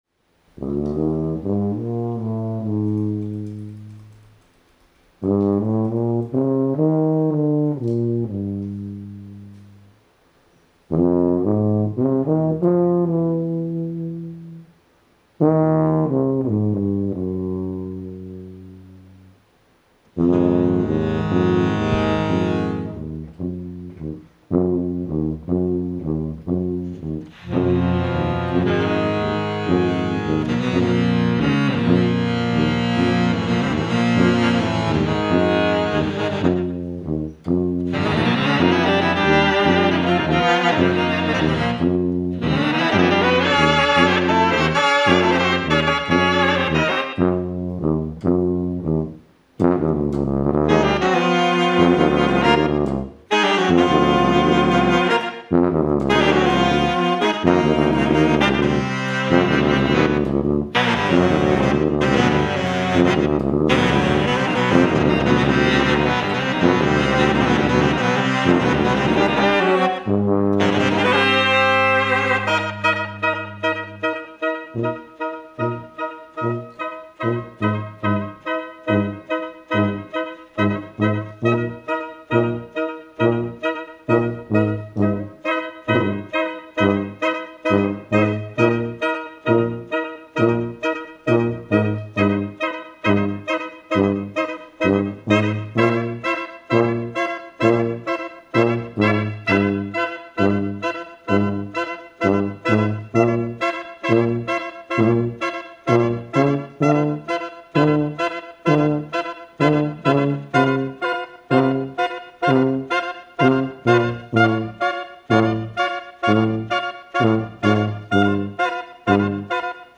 Recorded live at ABC NoRio in Manhattan
Stereo (Pro Tools)